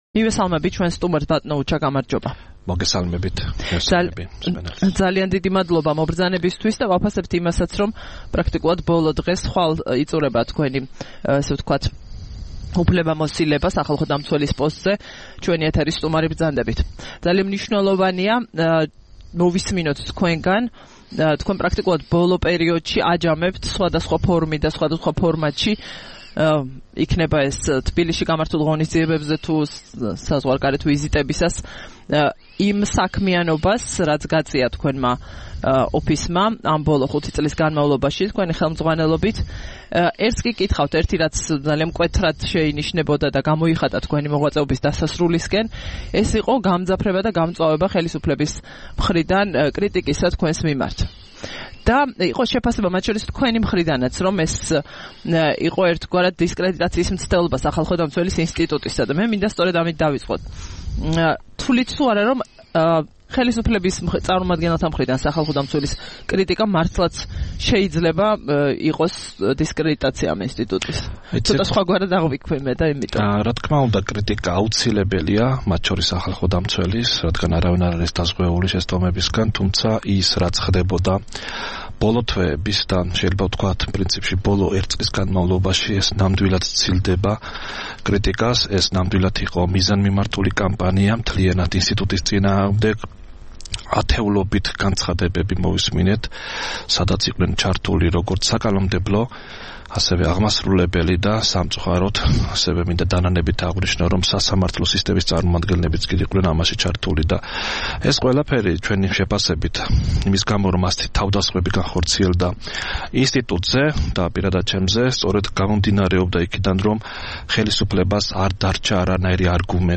6 დეკემბერს რადიო თავისუფლების "დილის საუბრების" სტუმარი იყო უჩა ნანუაშვილი, საქართველოს სახალხო დამცველი.